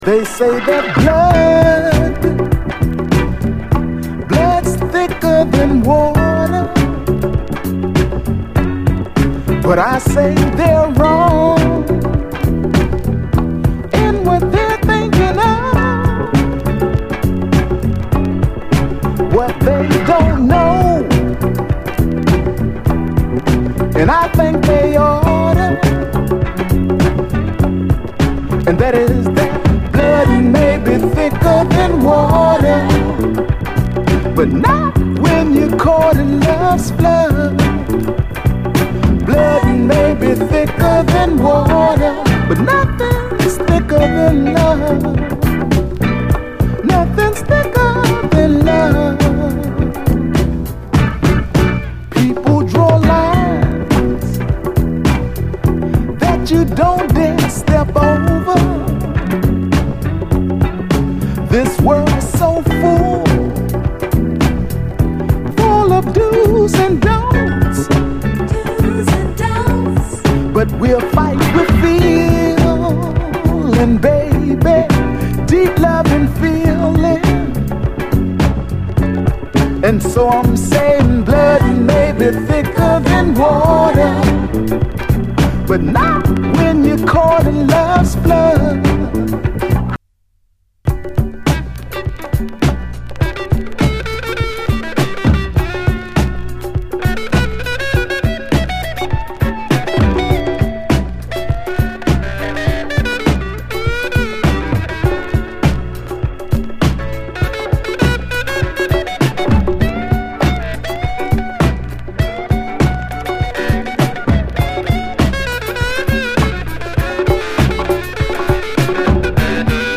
SOUL, 70's～ SOUL, 7INCH
彼の特徴的なボトム・アレンジもそのままの、グレイト・ニューソウル！